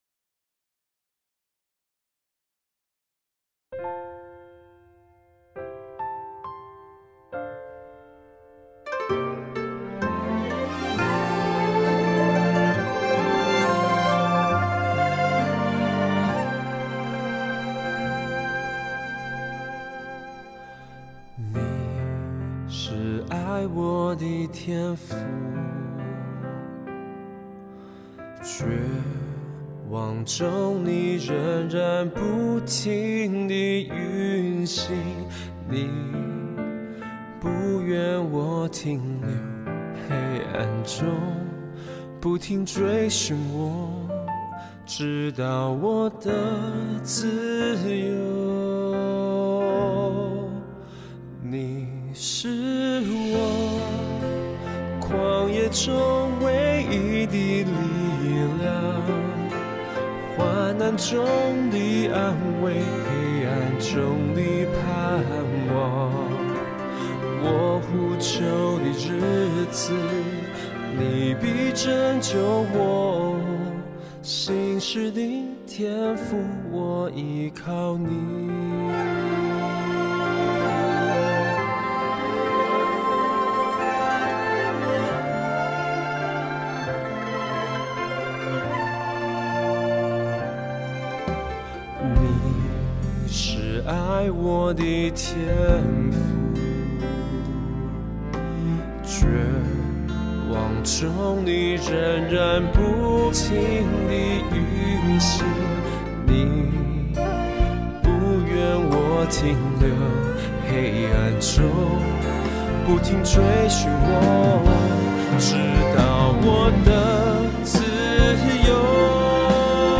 2023-07-14 敬拜诗歌 | 预告